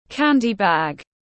Túi đựng kẹo tiếng anh gọi là candy bag, phiên âm tiếng anh đọc là /’kændi bæɡ/
Candy-bag-.mp3